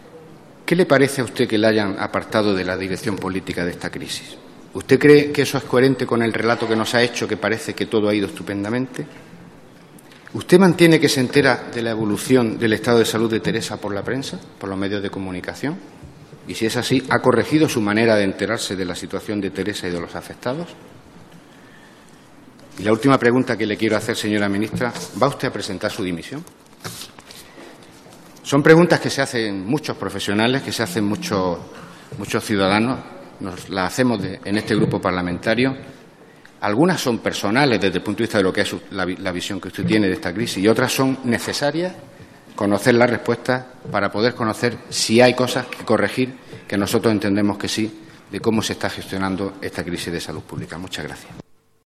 José M. Olmos pregunta a la ministra de Sanidad en la Comisión sobre el Ébola 15/10/2014